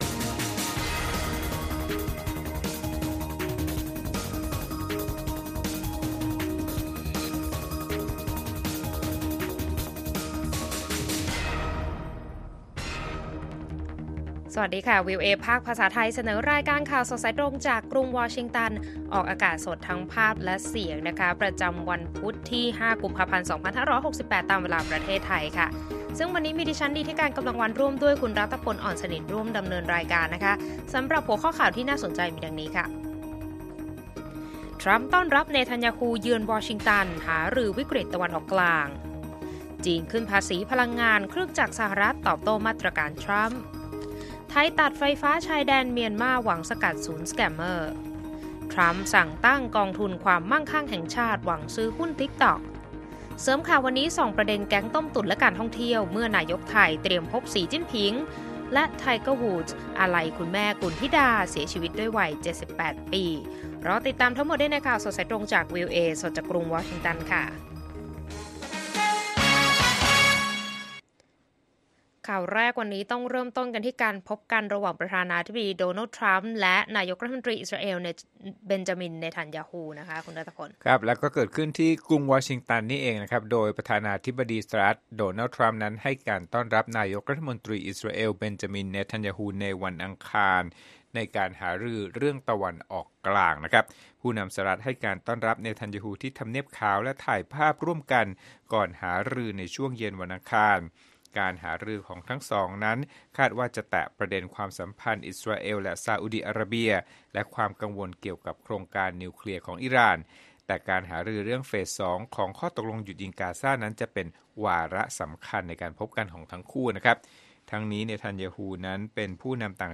ข่าวสดสายตรงจากวีโอเอ ภาคภาษาไทย พุธที่ 5 กุมภาพันธ์ 2568